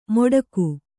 ♪ moḍaku